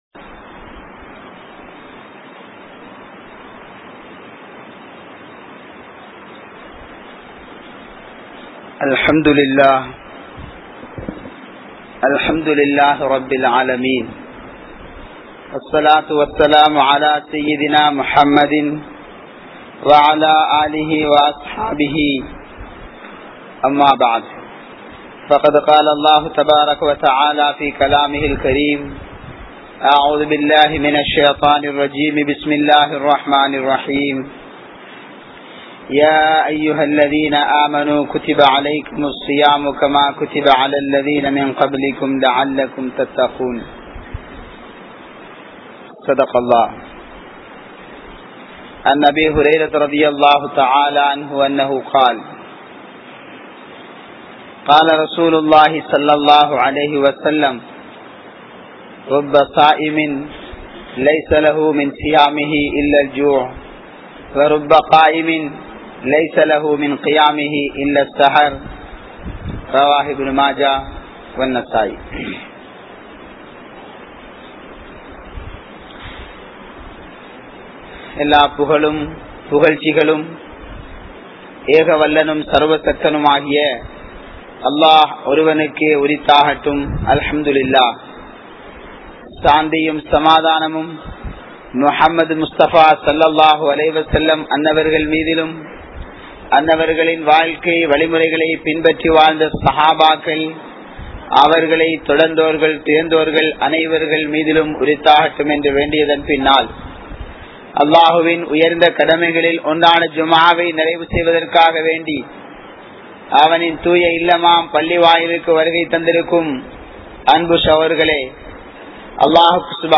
Haraamaana Ulaippu | Audio Bayans | All Ceylon Muslim Youth Community | Addalaichenai